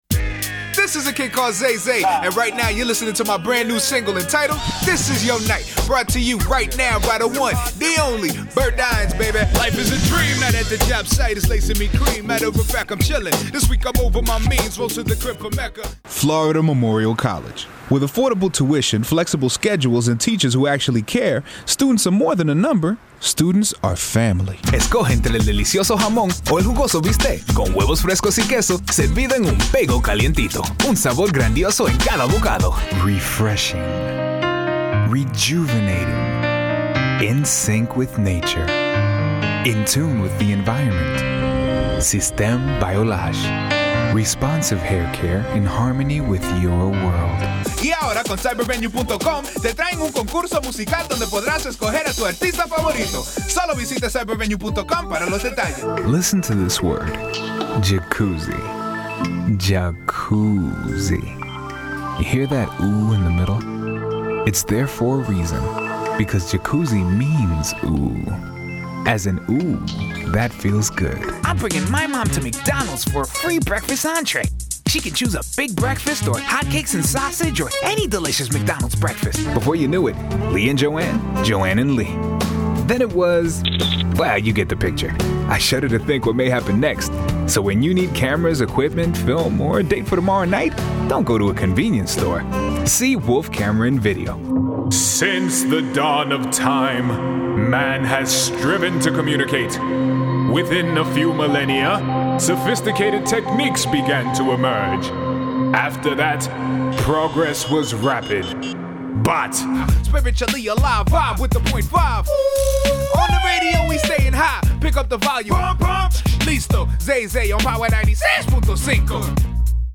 I have featured in everything from animated characters and commercial ads to documentary narrations and corporate training videos. Hear for yourself how I tailor my voice to suit any mood and message.